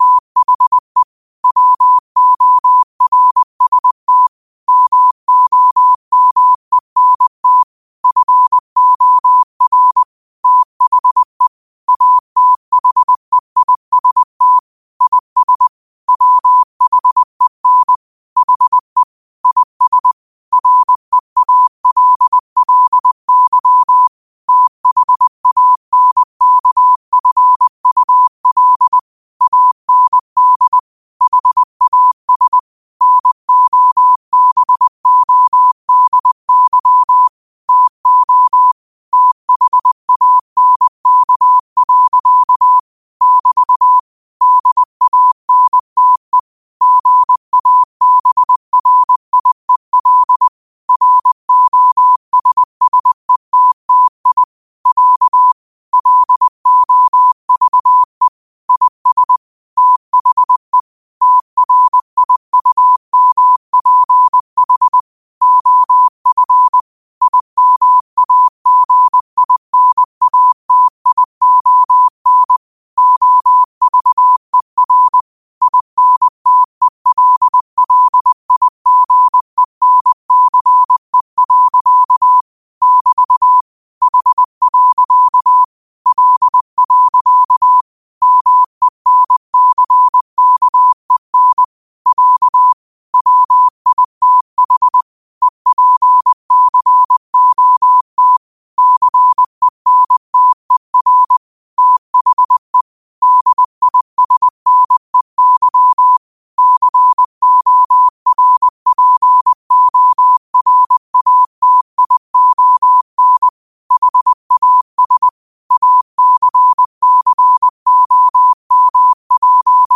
20 WPM morse code quotes for Fri, 15 Aug 2025 by QOTD at 20 WPM
Quotes for Fri, 15 Aug 2025 in Morse Code at 20 words per minute.